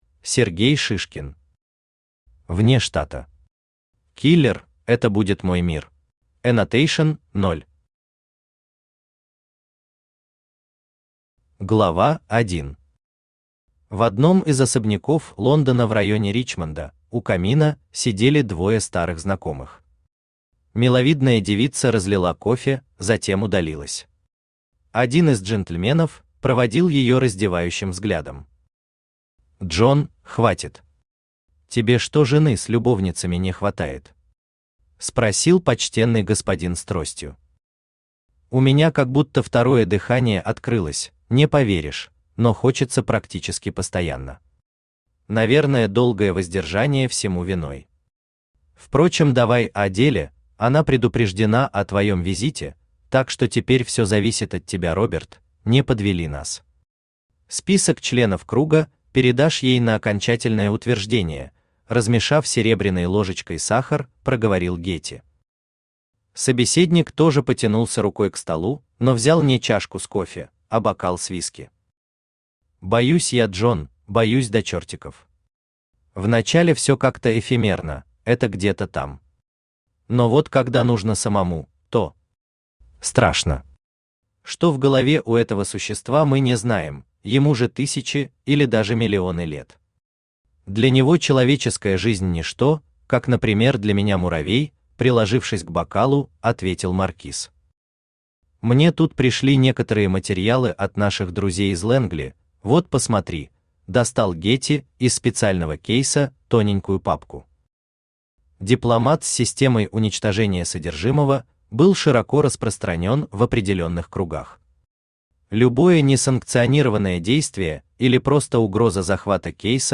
Аудиокнига Вне штата. Киллер – это будет мой мир!
Автор Сергей Шишкин Читает аудиокнигу Авточтец ЛитРес.